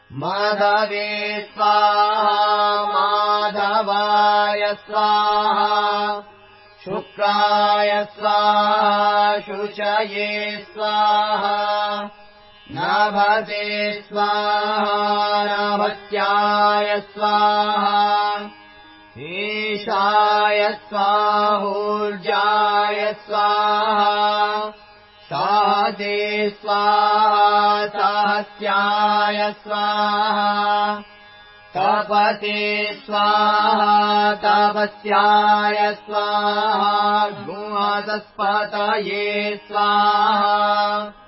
देवता: मासा देवताः ऋषि: प्रजापतिर्ऋषिः छन्द: भुरिगत्यष्टिः स्वर: गान्धारः
मन्त्र उच्चारण
Vedic maas mantr.mp3